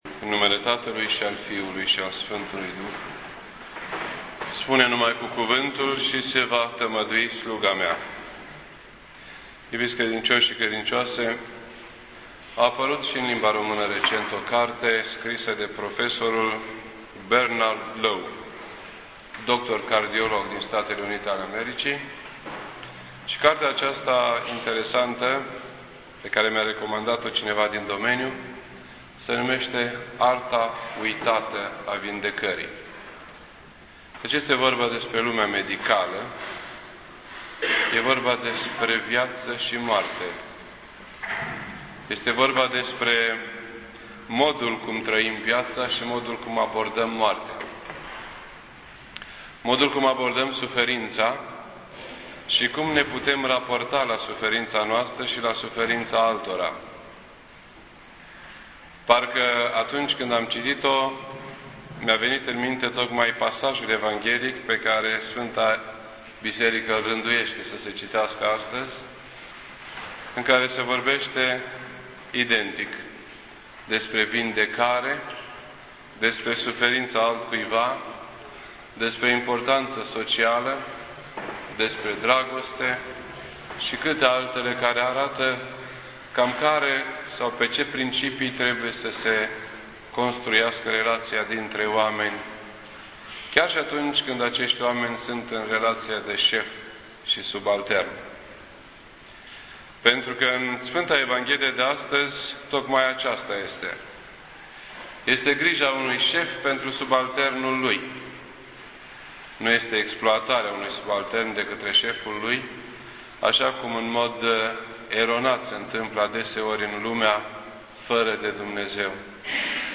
This entry was posted on Sunday, June 20th, 2010 at 7:43 PM and is filed under Predici ortodoxe in format audio.